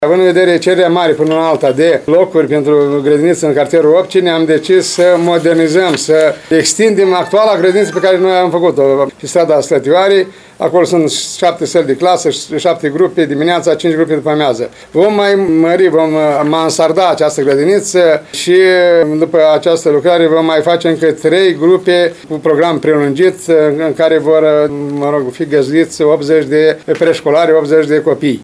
Primarul ION LUNGU a declarat astăzi că se lucrează la proiect și la studiile aferente, urmând ca investițiile să fie demarate primăvara viitoare.